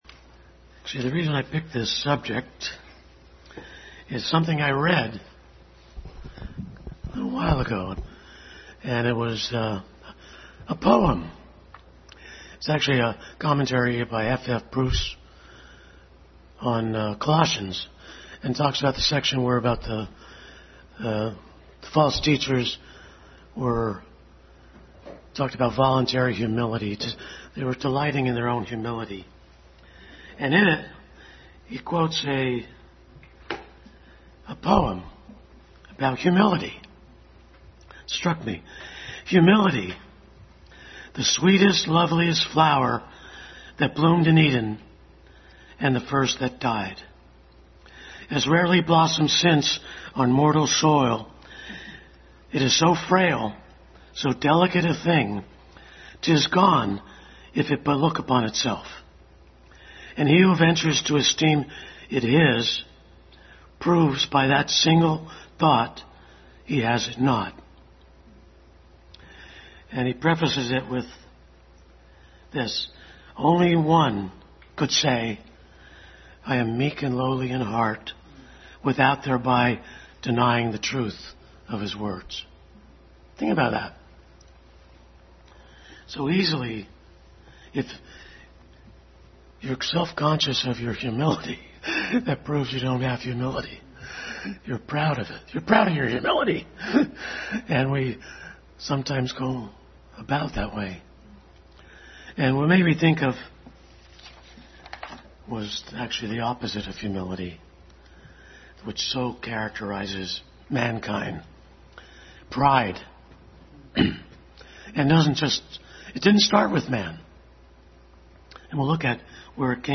Pride vs Humility Passage: Ezekiel 23:1-12, Isaiah 12:12-14, Genesis 3:5, 2 Chronicles 33:11-13, Luke 18:9-14 Service Type: Family Bible Hour Family Bible Hour message.